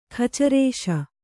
♪ khacarēśa